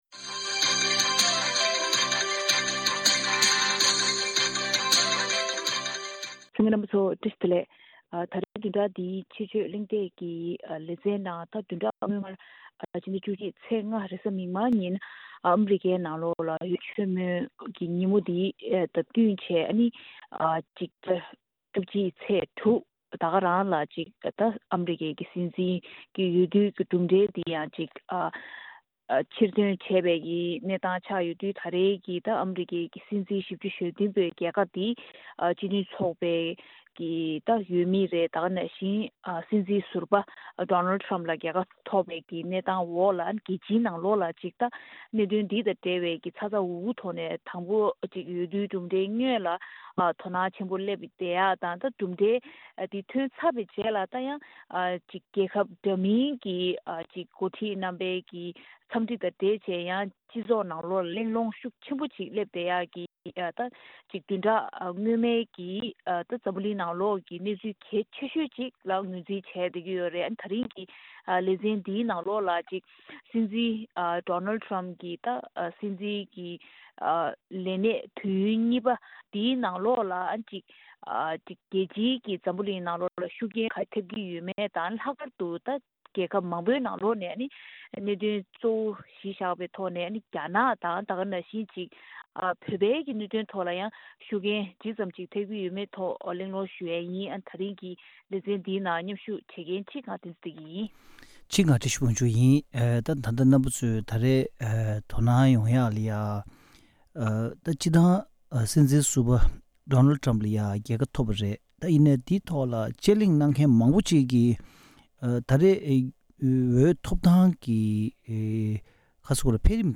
དཔྱད་གླེང་ཞུས་པ་ཞིག་གསན་རོགས་གནང་།།